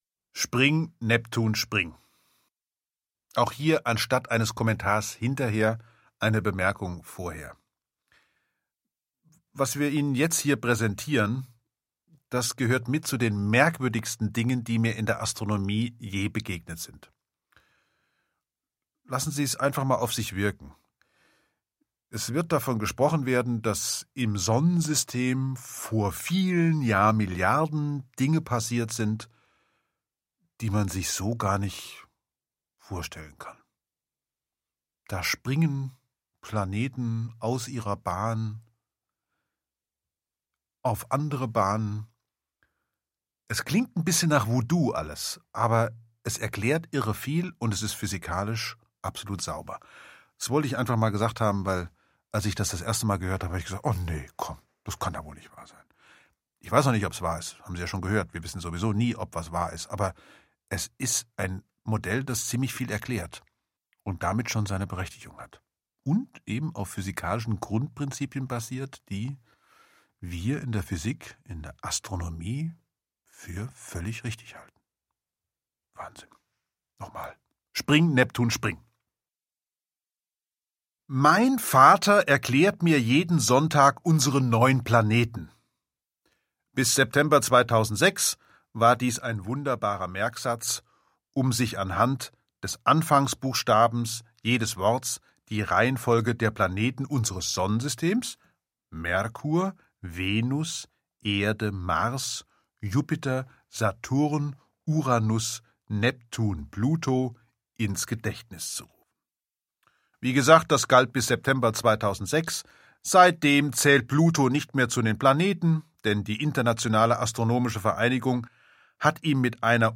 Mitarbeit Sprecher: Harald Lesch